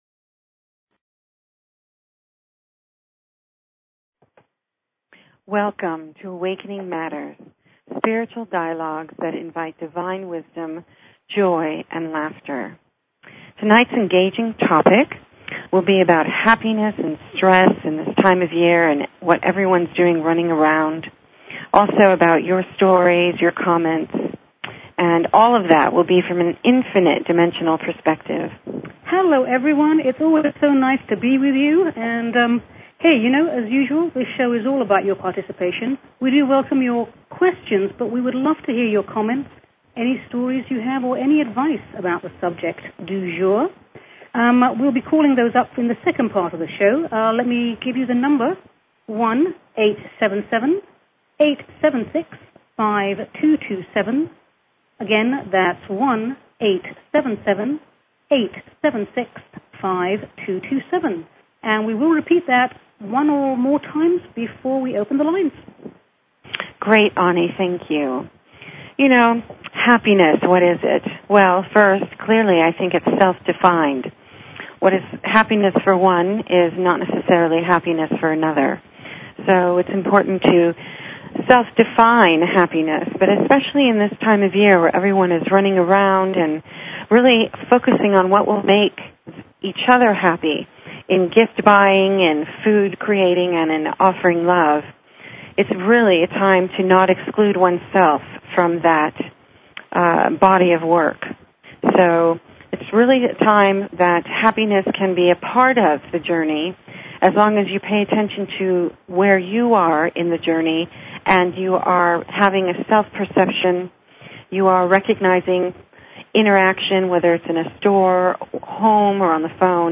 Talk Show Episode, Audio Podcast, Awakening_Matters and Courtesy of BBS Radio on , show guests , about , categorized as
A spiritual dialogue that invites divine wisdom, joy and laughter. Engaging topics of our humanity from an infinite dimensional perspective.